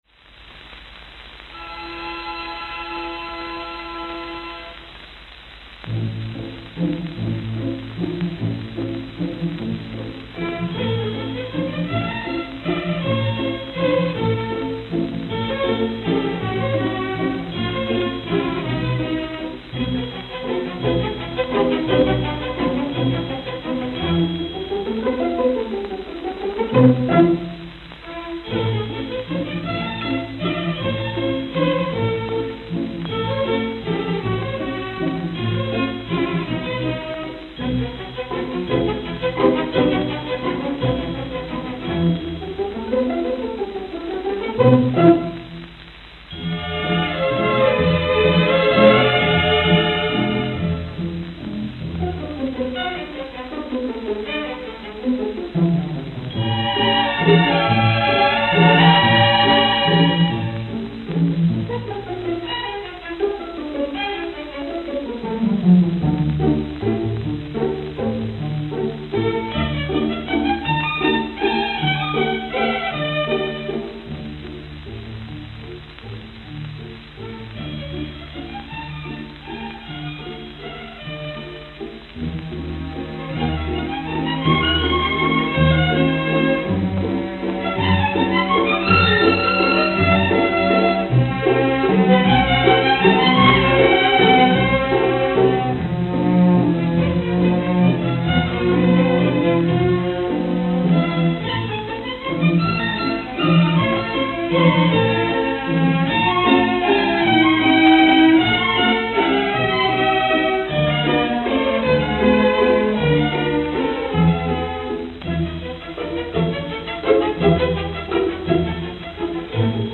Church Building.